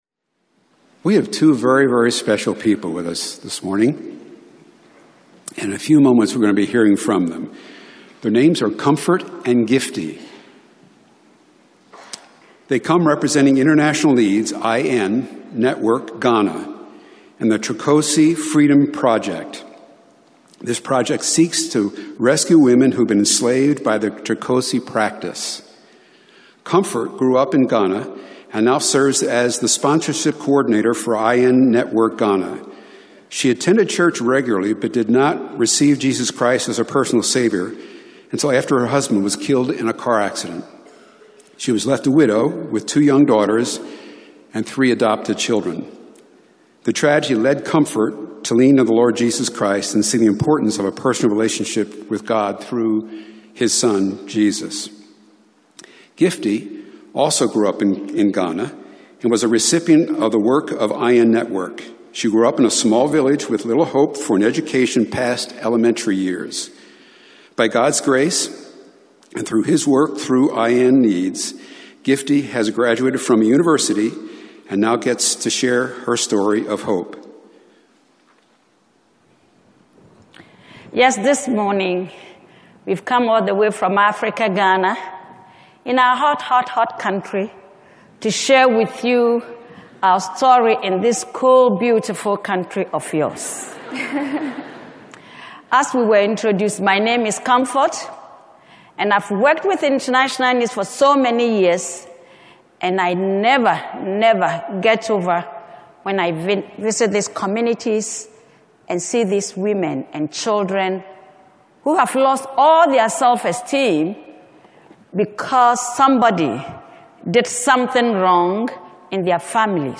Chapel: Trokosi Freedom Project
We welcome to chapel International Needs Network's Trokosi Freedom Project. Hear from women who were formerly a part of the Trokosi human slavery system in Ghana....